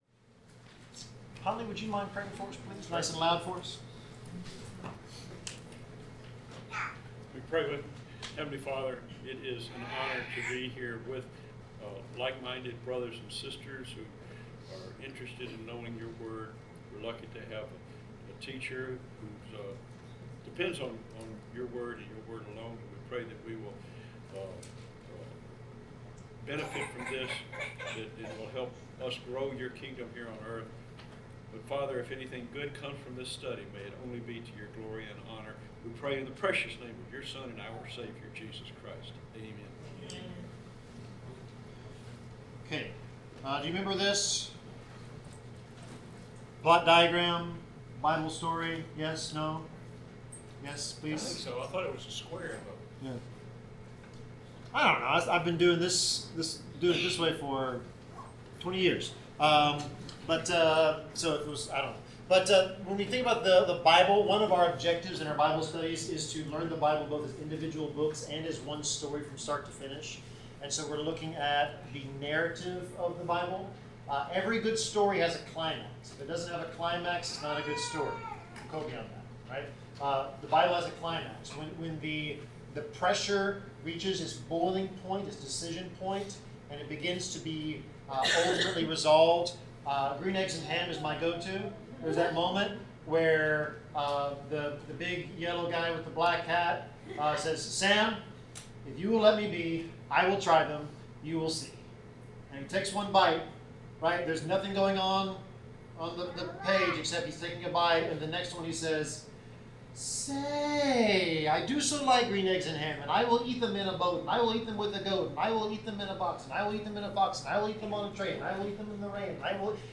Bible class: Nehemiah 5-7
Service Type: Bible Class